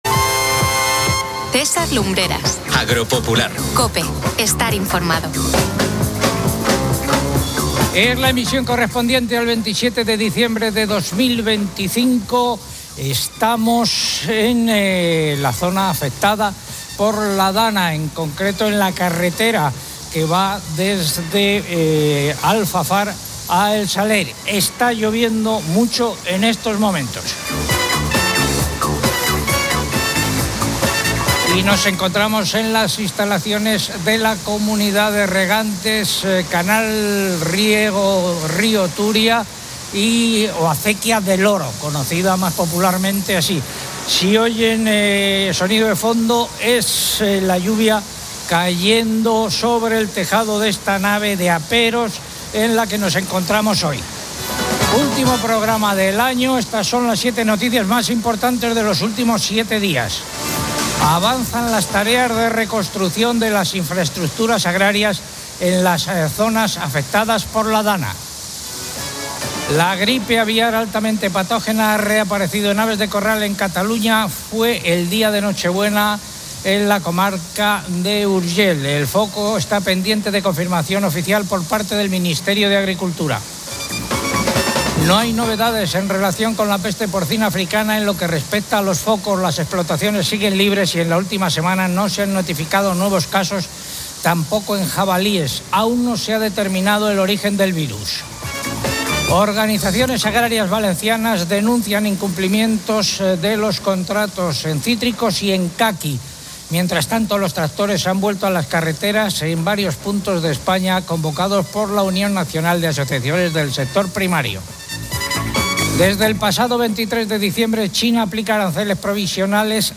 El programa del 27 de diciembre de 2025, emitido desde la zona DANA en Valencia, destaca el avance en la reconstrucción agrícola.